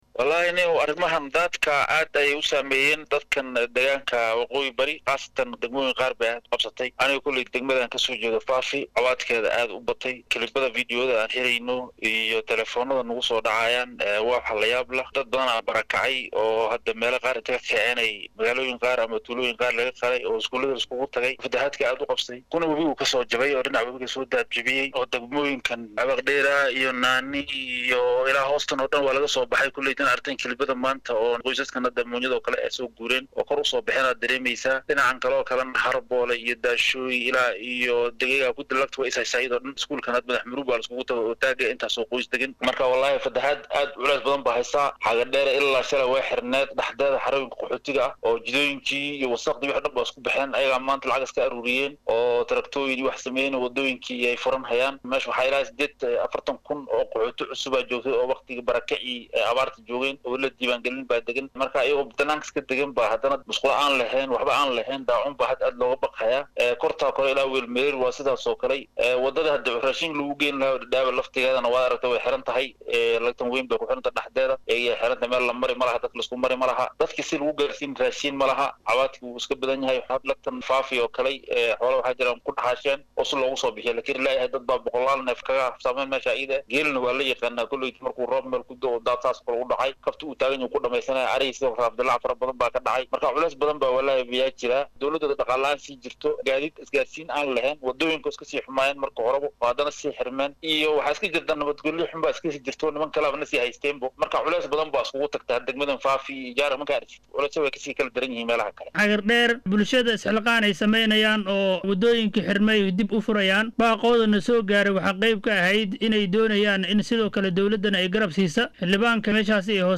DHAGEYSO:Xildhibaanka Fafi oo Star FM uga warramay saameynta daadadka